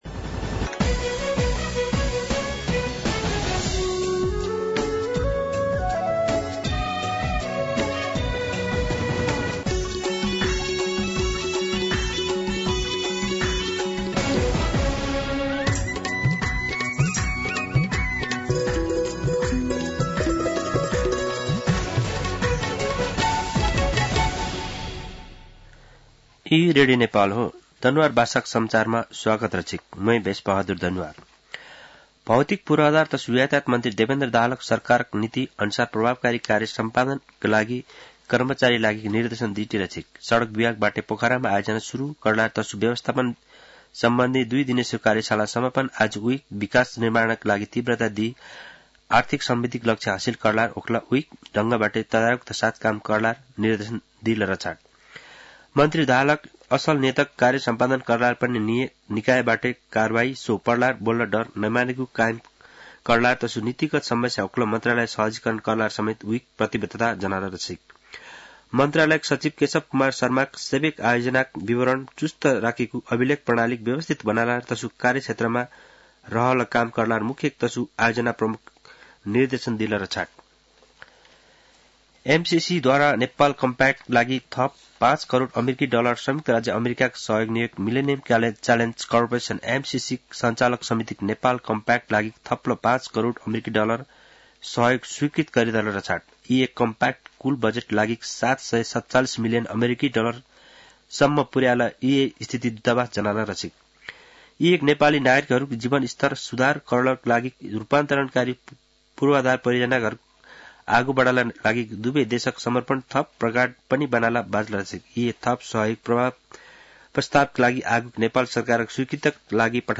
दनुवार भाषामा समाचार : २० पुष , २०८१
Danuwar-news.mp3